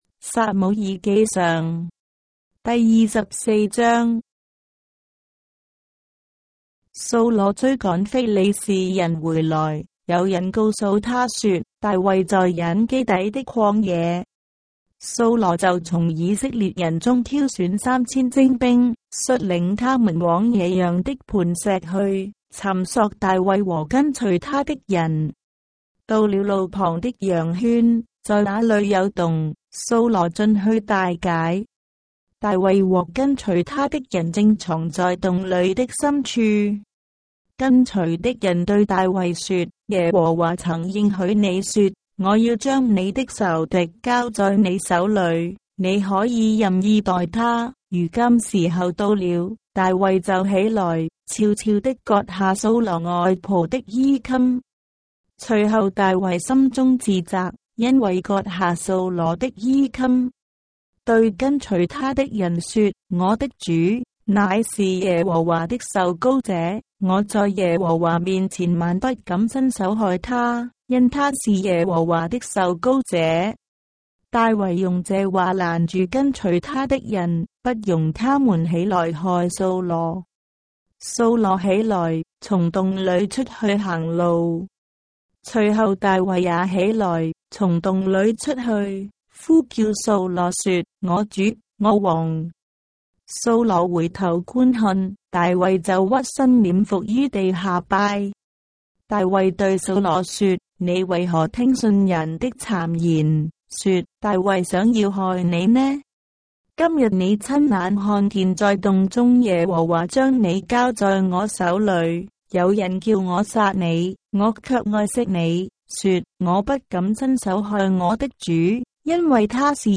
章的聖經在中國的語言，音頻旁白- 1 Samuel, chapter 24 of the Holy Bible in Traditional Chinese